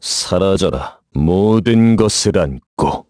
Clause_ice-Vox_Skill6_kr.wav